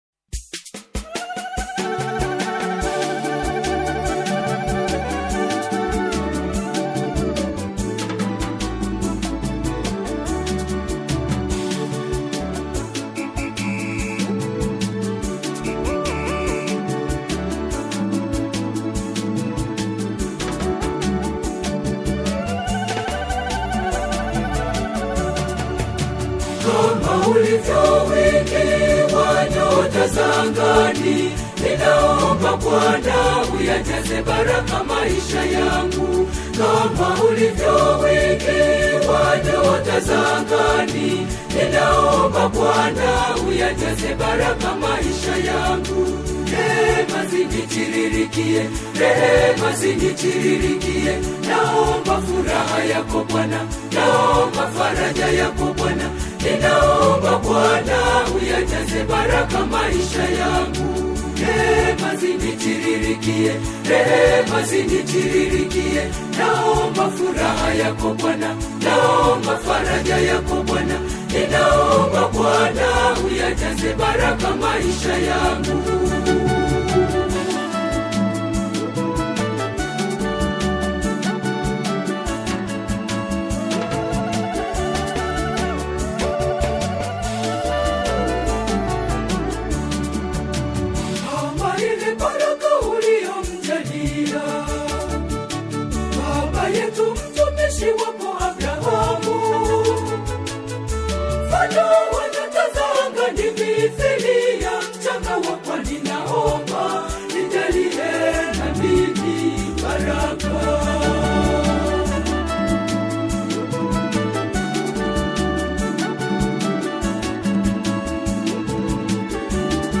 Looking for an intriguing prayer song to download?
soothing harmonies